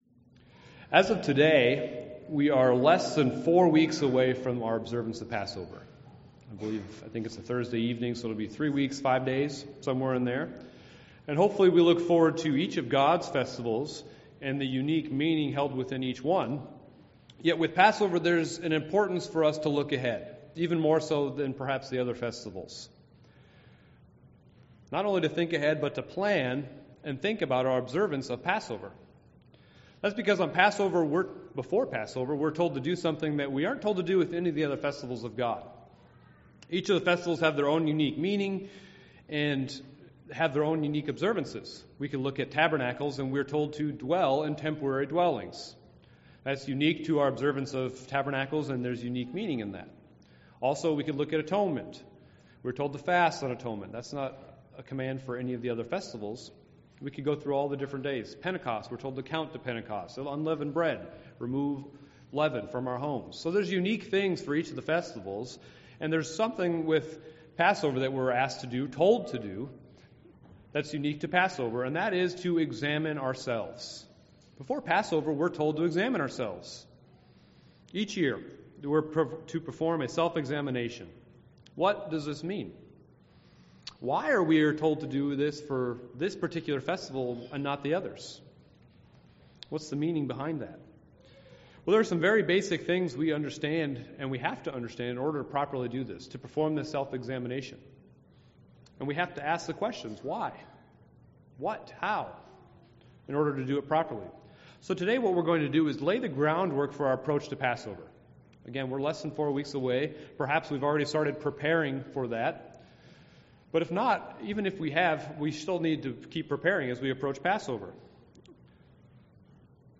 This sermons looks to lay the foundation to answer these questions and hopefully set the tone for a spiritually renewing Passover.